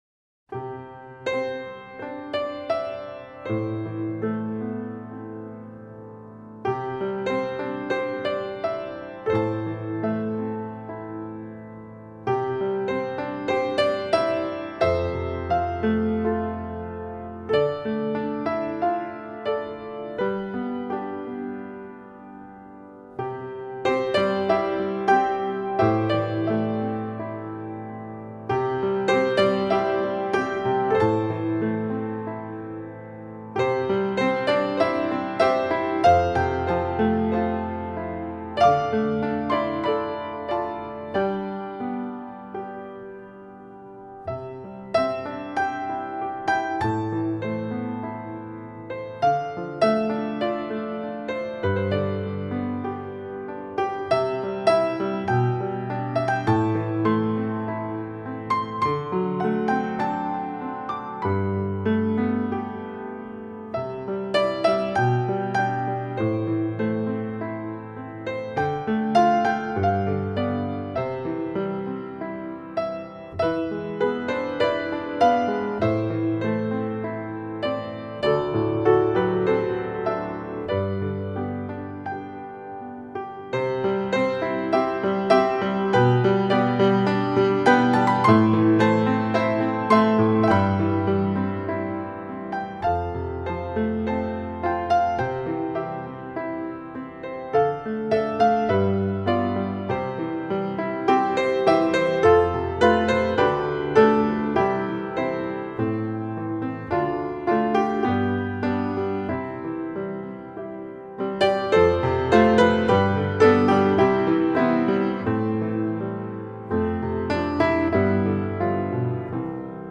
純淨鋼琴與心靈之海的溫柔對話